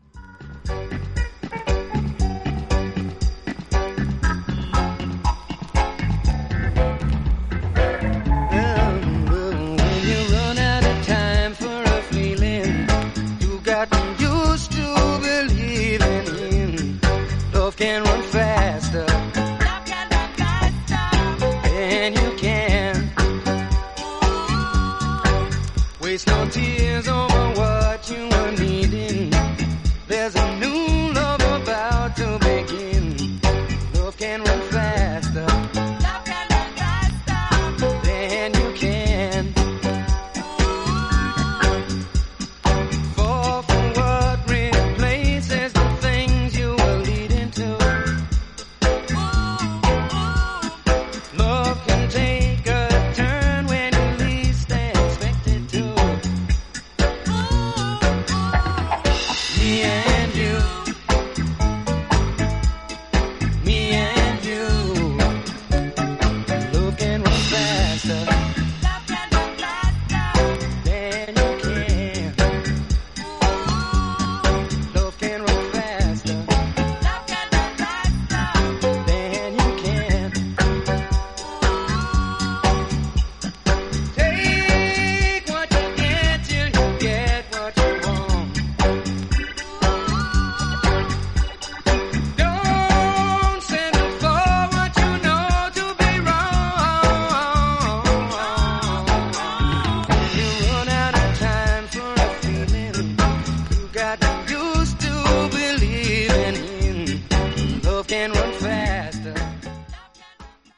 イギリス出身のブルーアイドソウル・シンガー